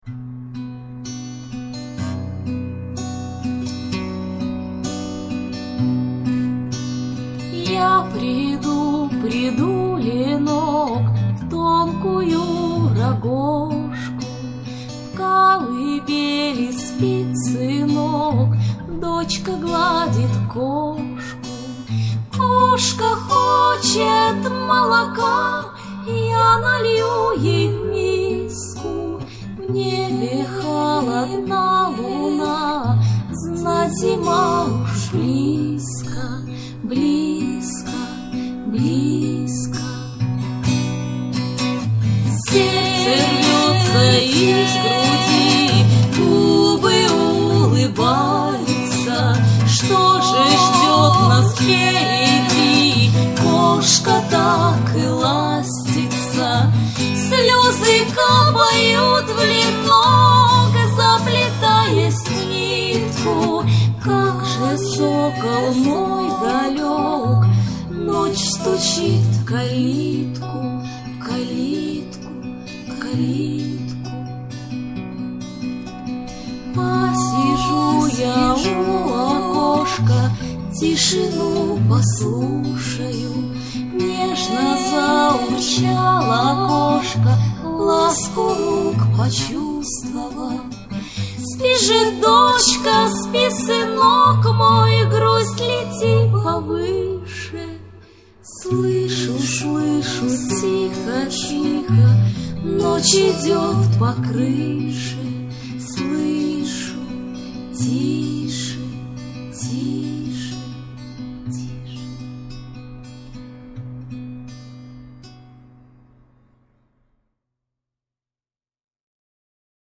душевно так...лирично...и тоскливо...
неспешно так...
дуэт ...блин...не помню - как вспомню - скажу
точно! очень красиво поют